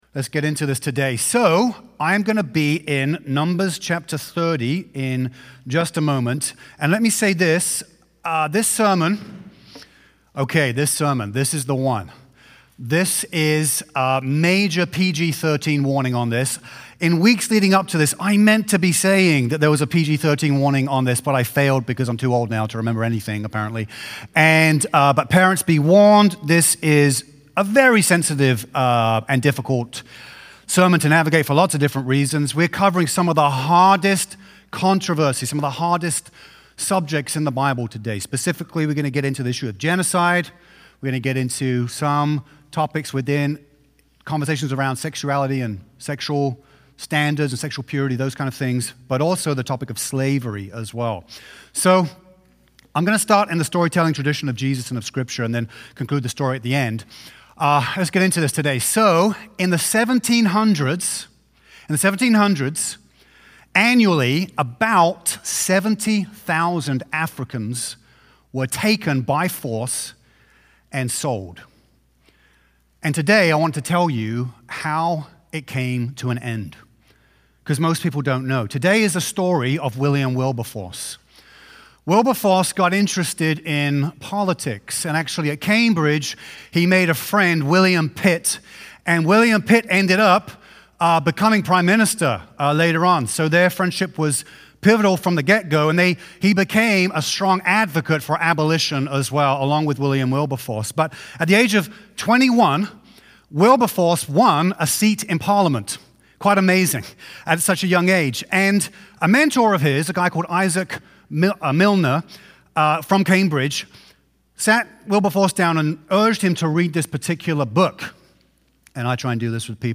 NOV-2-Full-Sermon.mp3